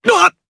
Kibera-Vox_Damage_jp_02.wav